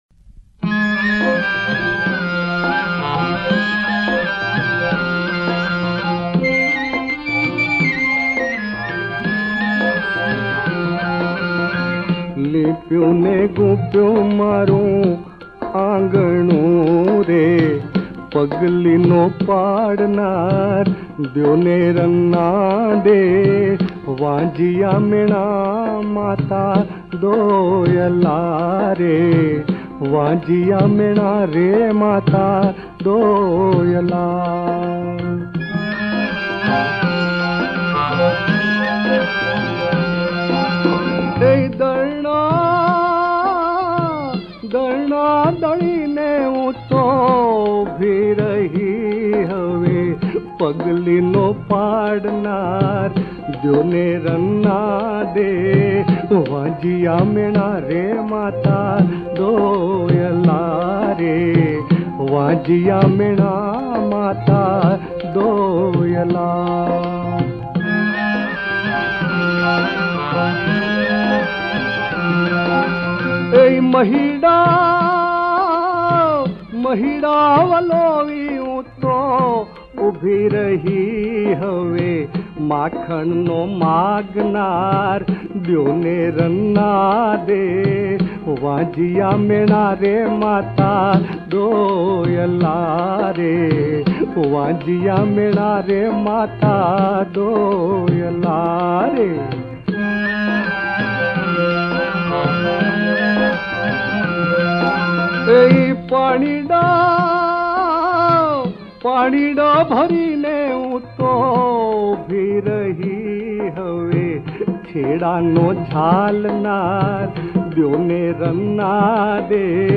ગીત સંગીત ગરબા - Garba